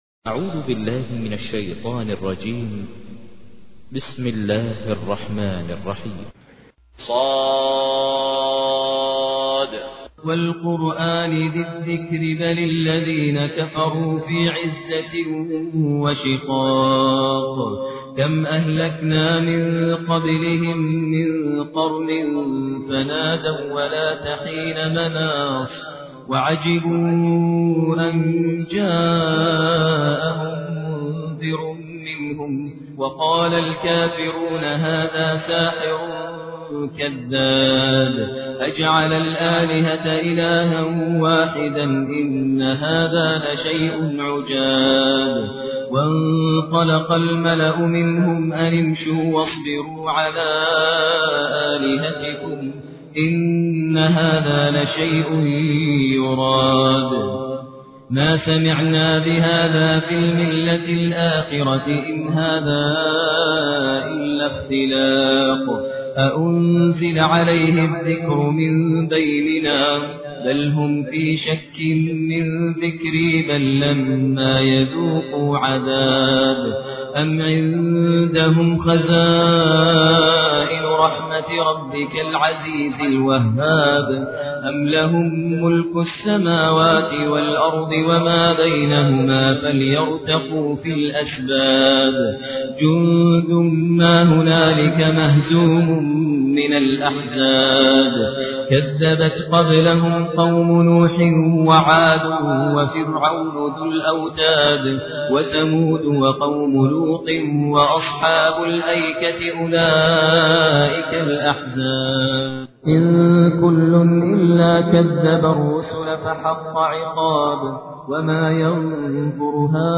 تحميل : 38. سورة ص / القارئ ماهر المعيقلي / القرآن الكريم / موقع يا حسين